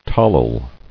[tol·yl]